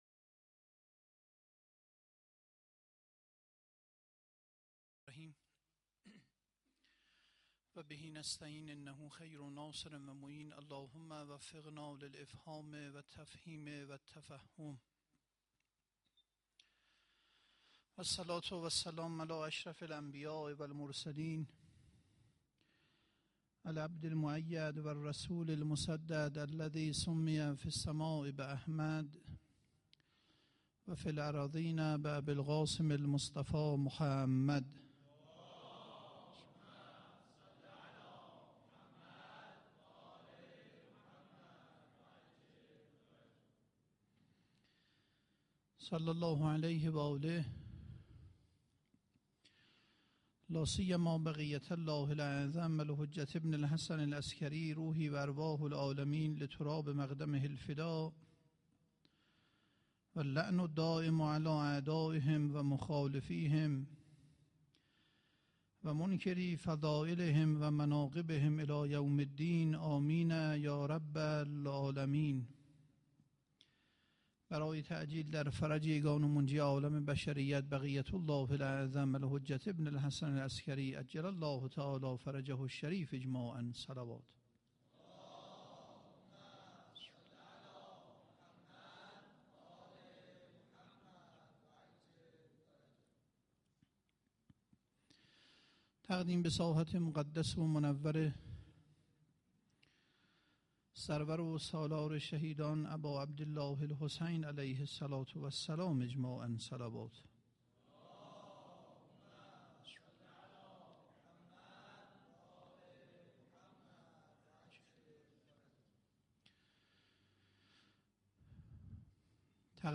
سخنرانی2.mp3